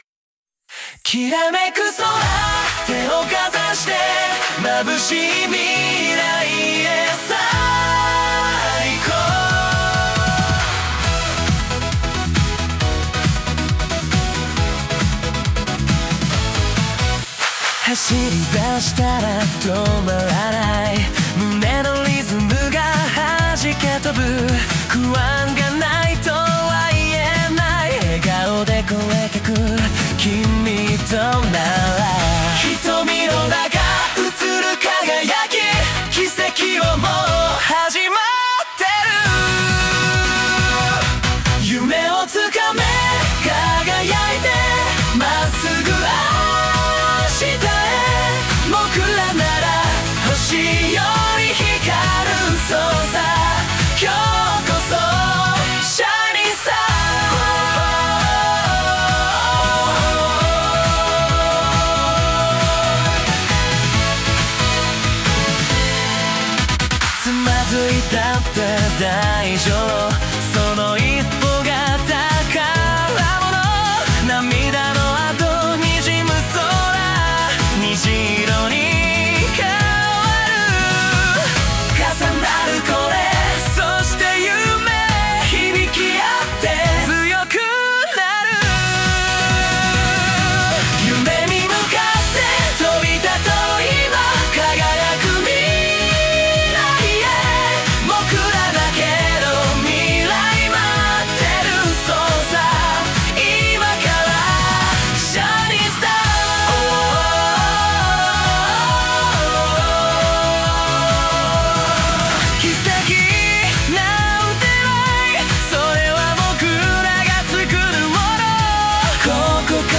男性ボーカル
J-POP
男性ポップグループ